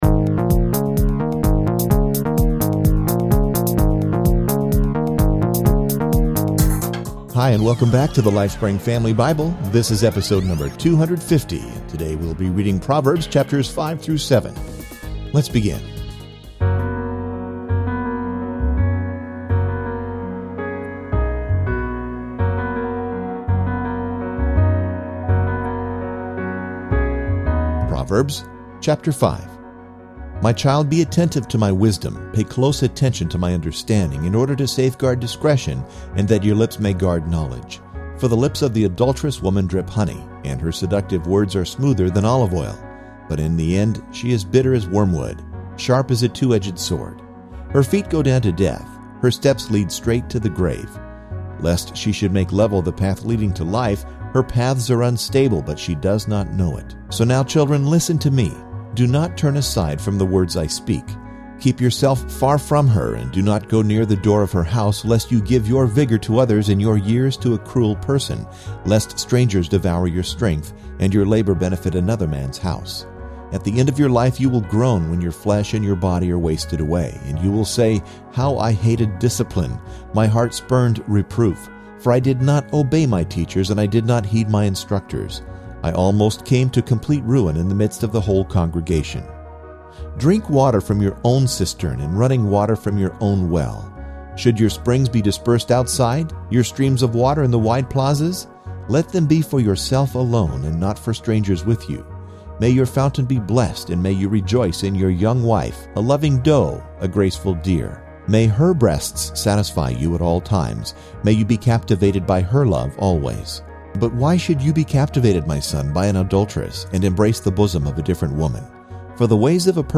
Lifespring! Family Audio Bible